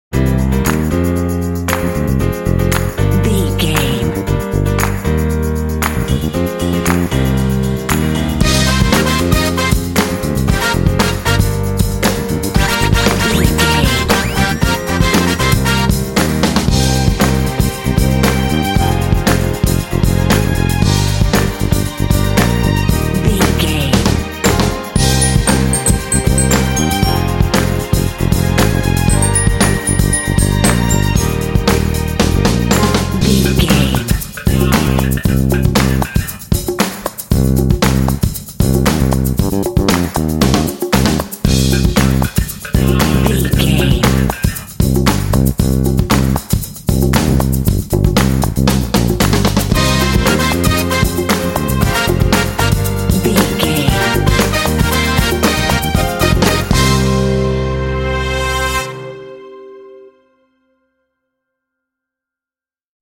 This groovy motown track comes with several layers
Aeolian/Minor
funky
happy
bouncy
groovy
piano
bass guitar
drums
brass
synthesiser
Funk